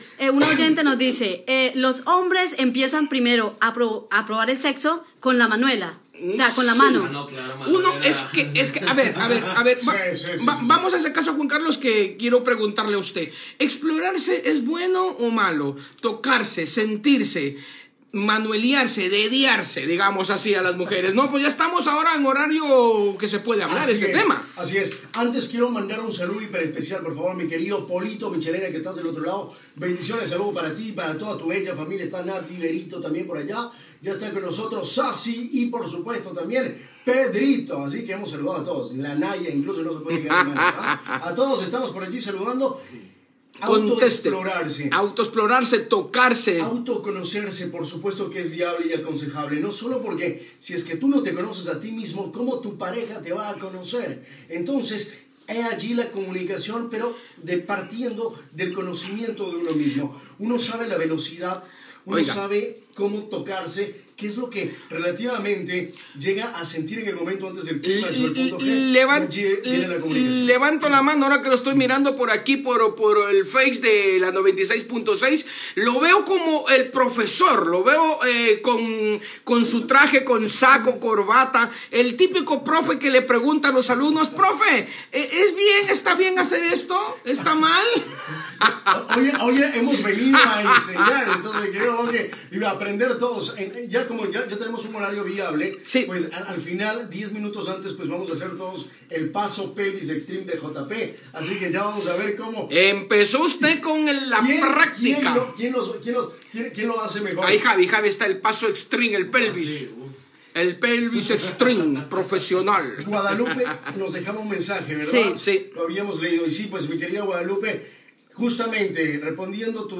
Diàleg sobre la masturbació